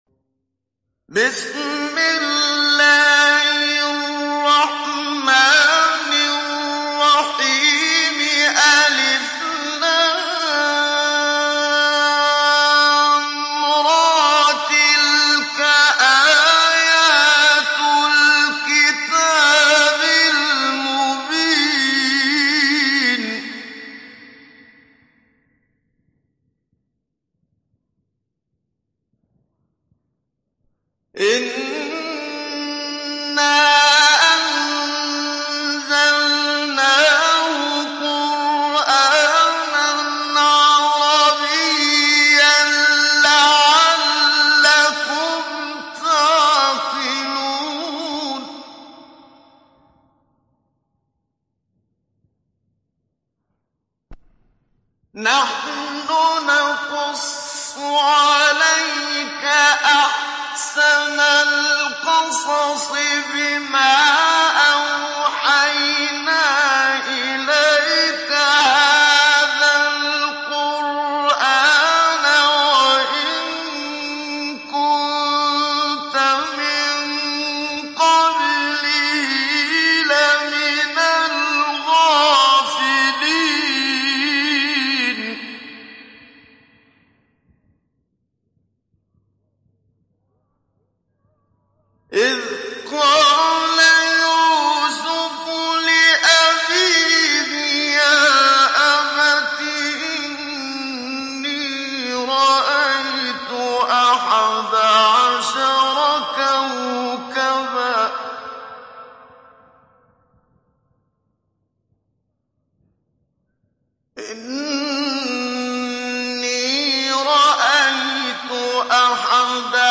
Télécharger Sourate Yusuf Muhammad Siddiq Minshawi Mujawwad